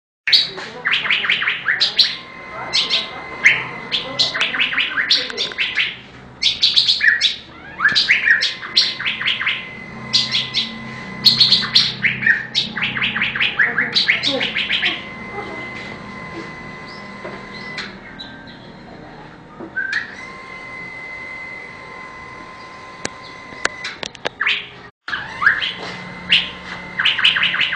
1. Mp3 Suara Burung Cucak Biru Jantan
Suara Cucak Biru Gacor
Suara Cucak Biru Asli Alam
Masteran Burung Cucak Biru